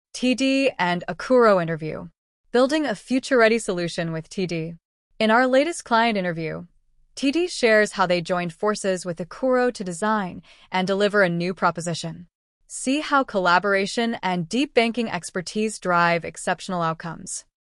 • Interviews
TD interview audio.m4a